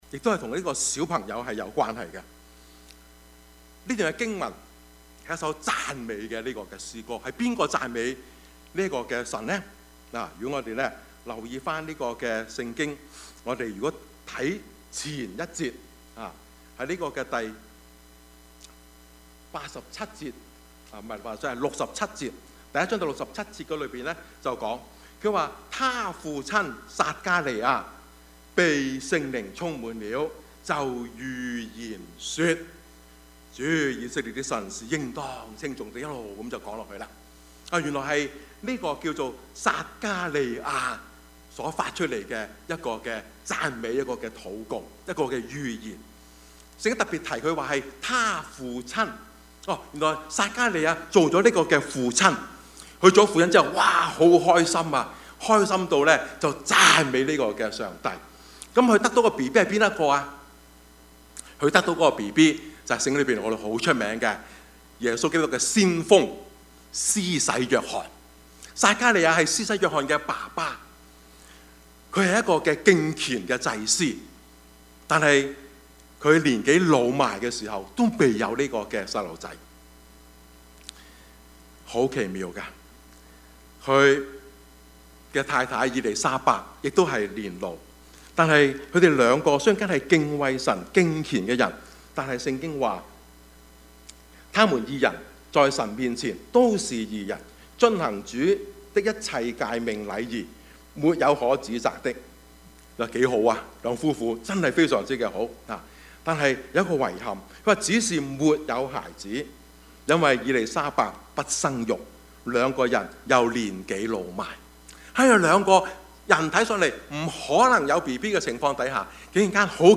Service Type: 主日崇拜
Topics: 主日證道 « 世界末日?